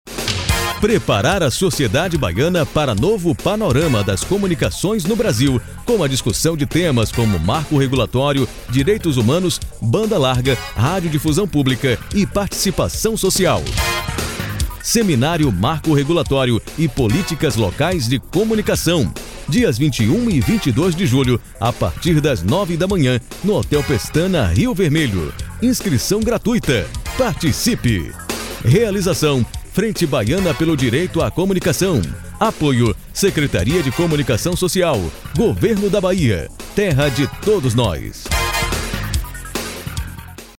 seminario-marco-regulatorio-chamada-radio.mp3